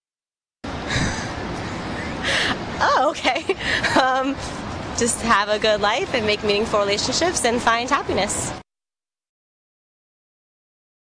2/5 - Now listen to her answer.